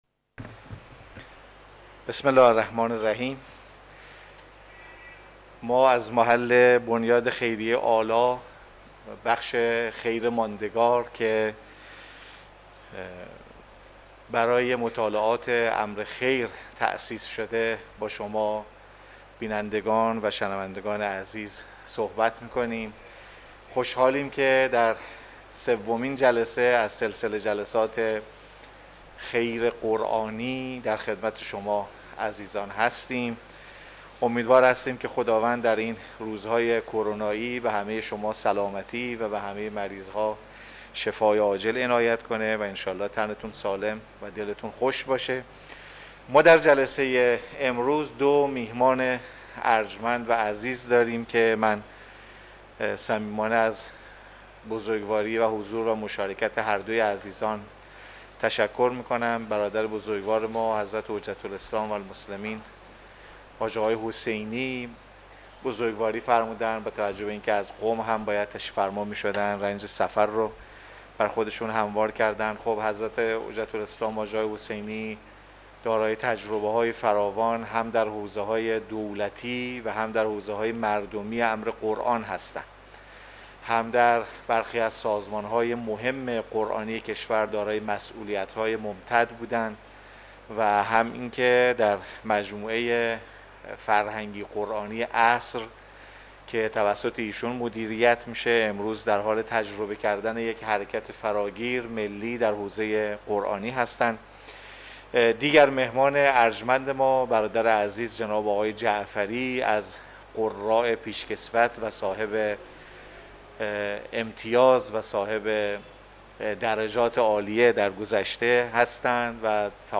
سومین نشست از سمینار علمی خیر قرآنی بر بستر مشارکت‌های مردمی با حضور دو فعال قرآنی کشورمان برگزار شد. طی این نشست راهکارهای جلب مشارکت مردمی برای امور قرآنی تشریح و چگونگی جلب منابع مالی برای یکی از بزرگترین مؤسسات قرآنی و جذب مشارکت خیرین برای اجرای جشنواره تلاوت‌های مجلسی مطرح شد.